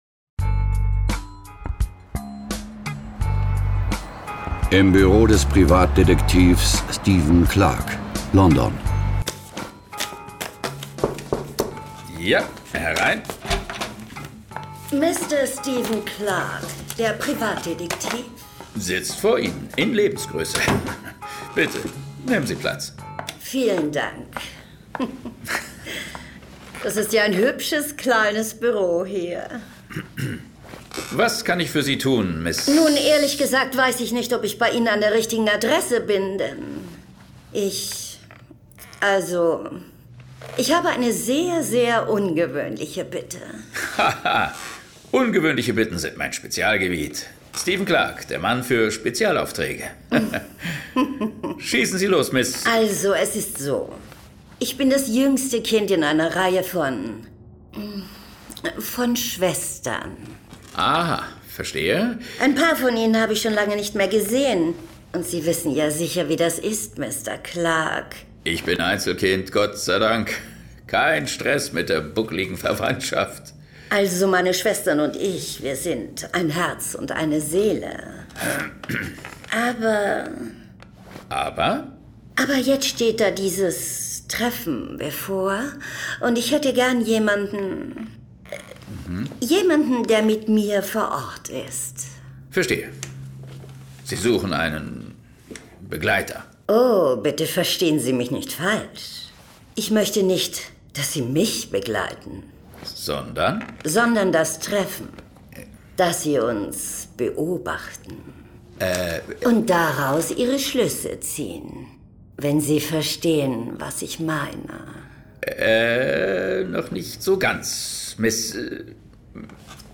John Sinclair - Folge 167 Teufelsspuk und Killer-Strigen. Hörspiel.